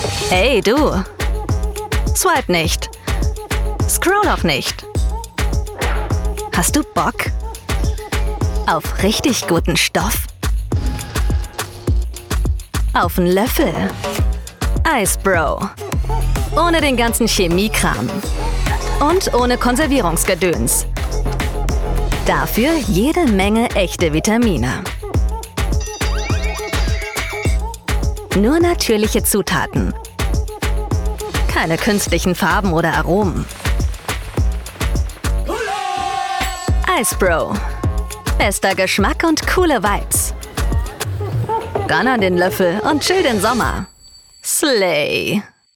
dunkel, sonor, souverän, markant, sehr variabel
Werbung Eis fresh
Commercial (Werbung)